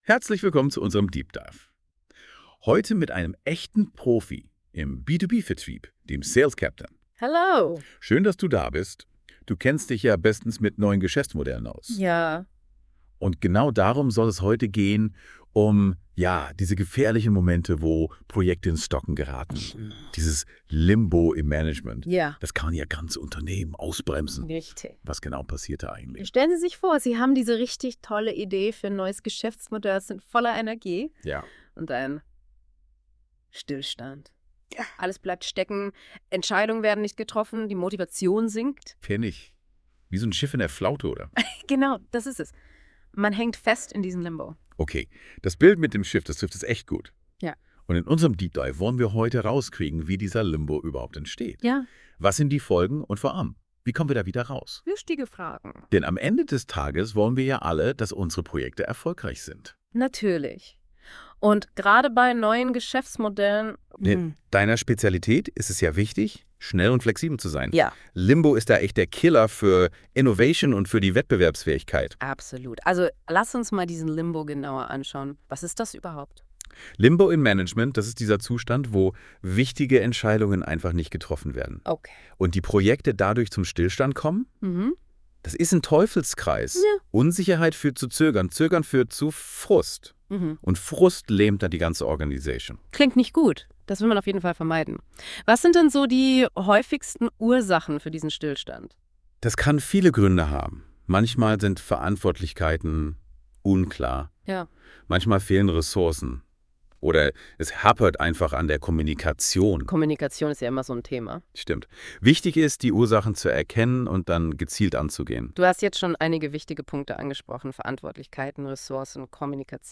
Hören Sie sich hier einen von künstlicher Intelligenz generierten Podcast zu diesem Thema an, der mit fiktiven Situationen angereichert ist: